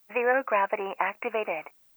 Zero Gravity Activated 1.wav